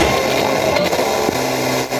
120BPMRAD0-L.wav